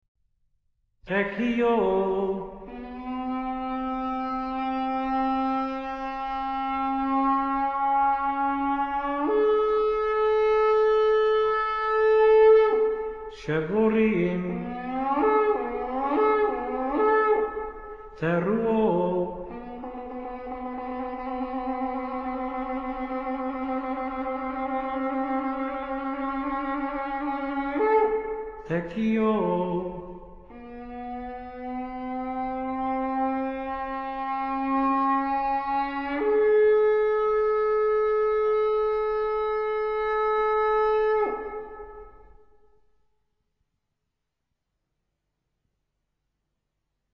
chofar[1].mp3